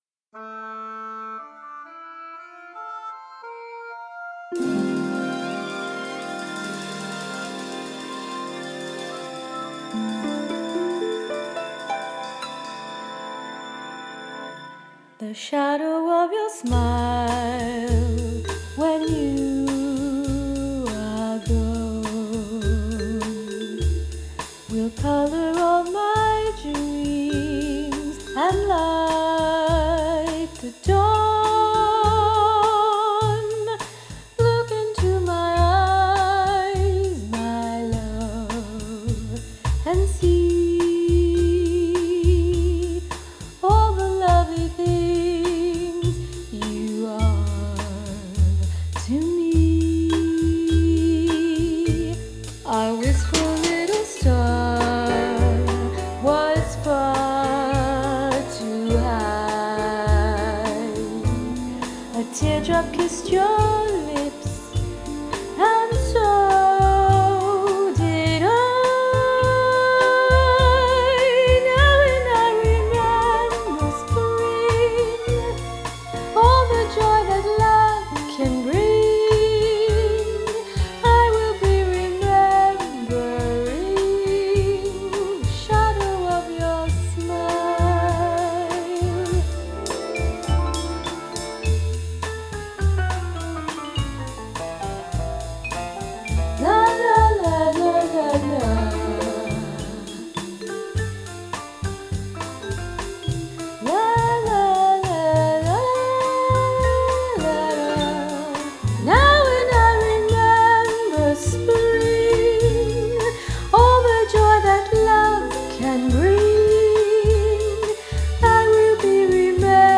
vocals and arrangement